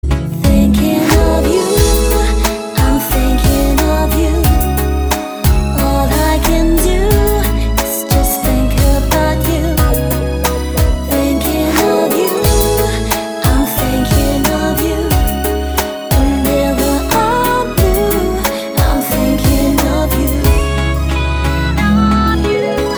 лирика
спокойные
медленные
лирическая композиция